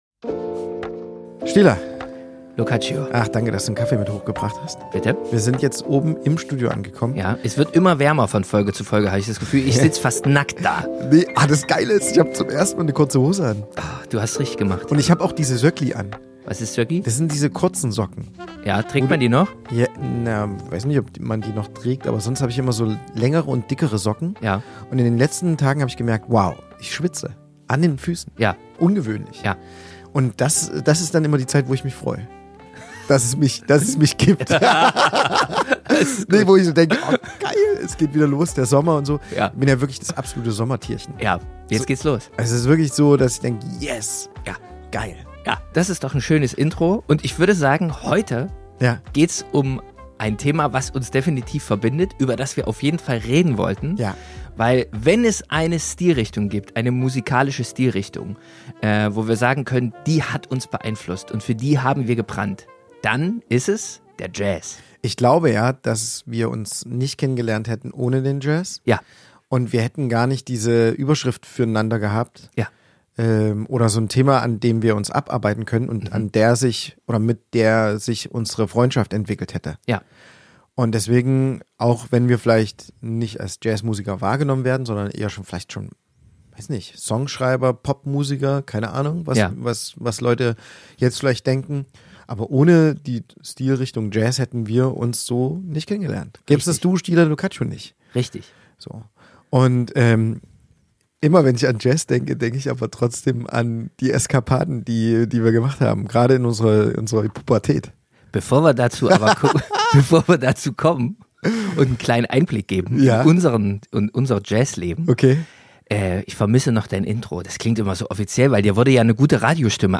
Eine Folge zwischen Saxofon, Konzerten in Hotelbars und FKK – wie immer nackt, ehrlich und leicht übersteuert.